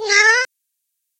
PixelPerfectionCE/assets/minecraft/sounds/mob/cat/meow2.ogg at ca8d4aeecf25d6a4cc299228cb4a1ef6ff41196e
meow2.ogg